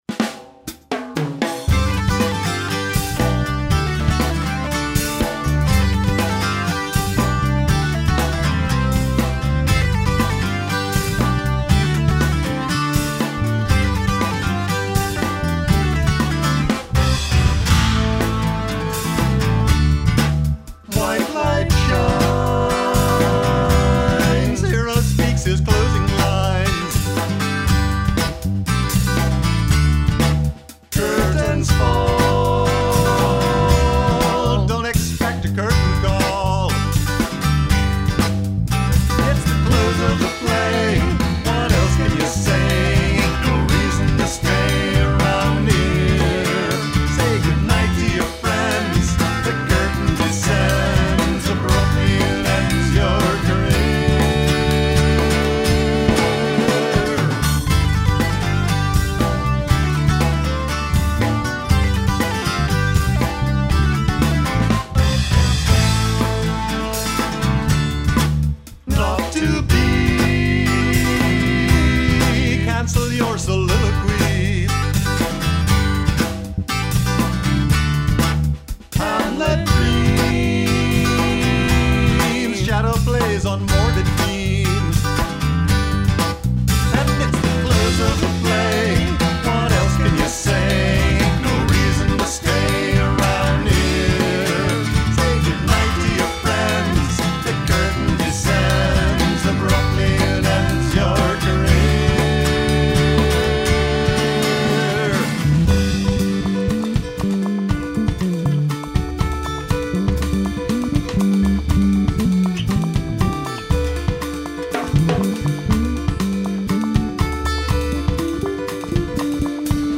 (2006 - demo)
fiddle
bass